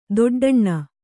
♪ doḍḍaṇṇa